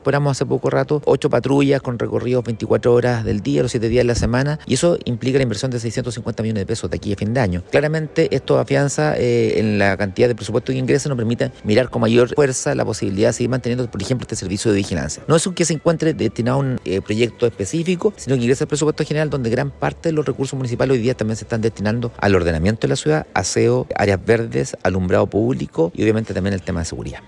El alcalde subrogante de Concepción, Aldo Mardones, señaló que la destinación de los recursos, que supera los mil millones de pesos, va directamente al presupuesto anual para proyectos propios del municipio.